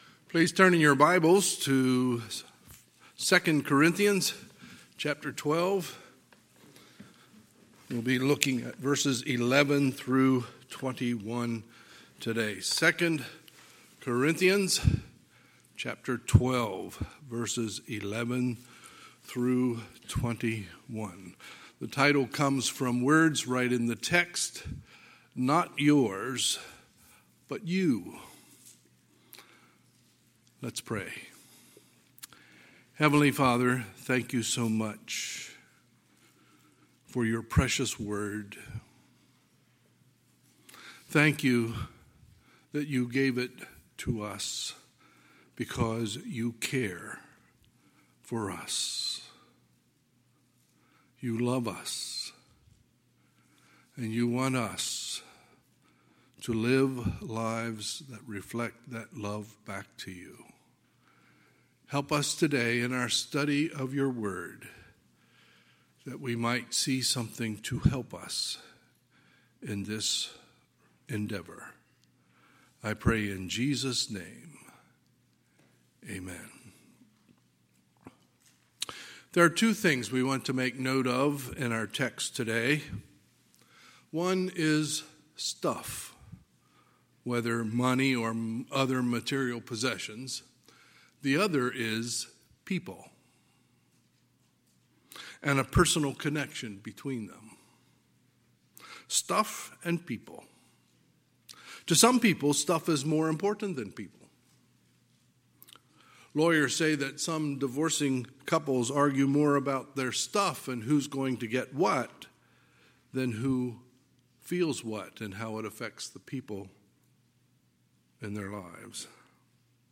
Sunday, November 8, 2020 – Sunday Morning Service